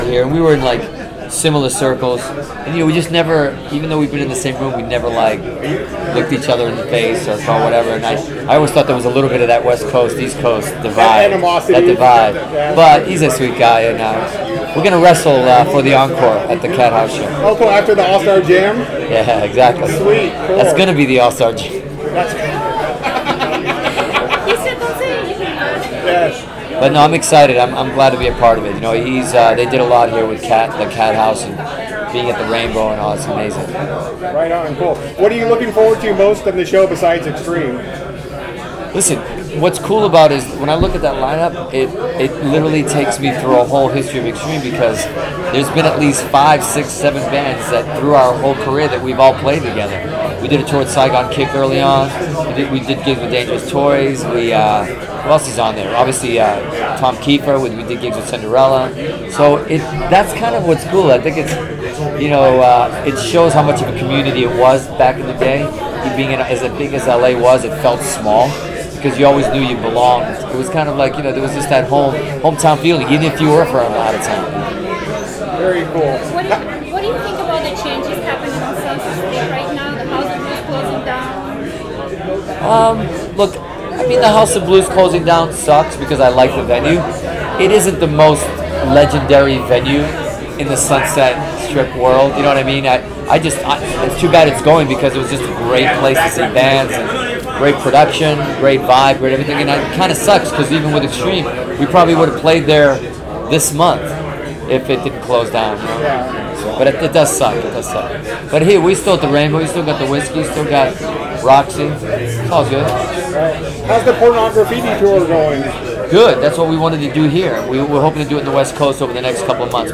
During the CATHOUSE LIVE press conference that took place at the Rainbow this afternoon, CRN interviewed Riki Rachtman and Cathouse Live band members including (links are audio files of the interviews):